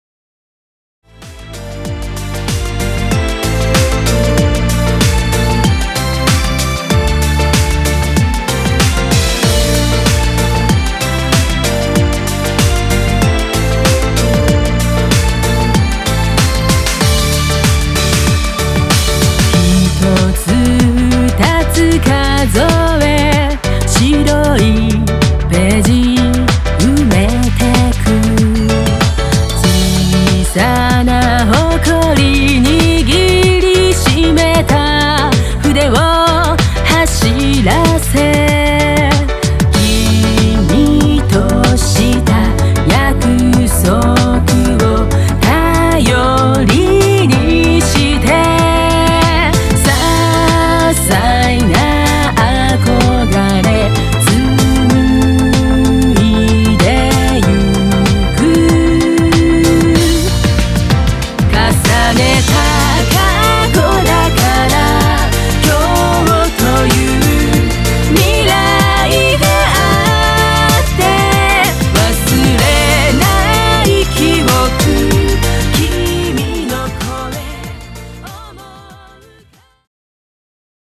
東方フルボーカルアレンジアルバム 第六弾！！
情け無用のココロ×ポップス！